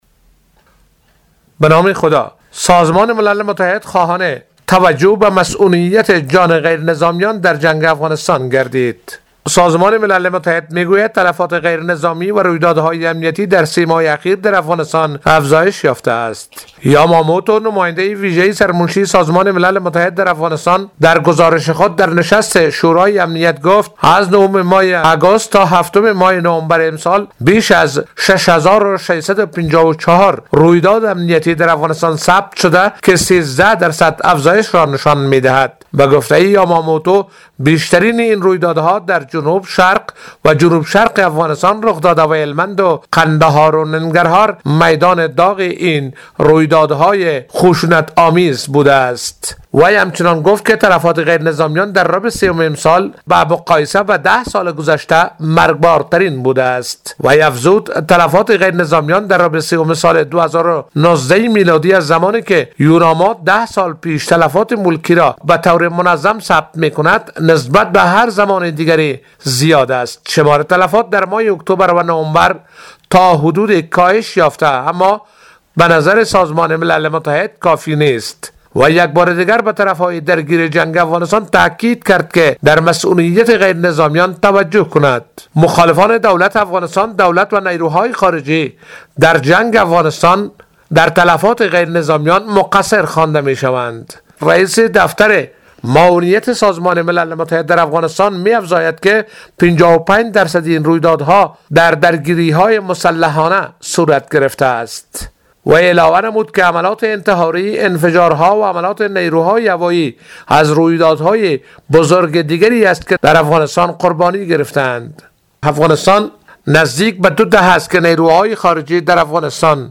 گزاریش
از کابل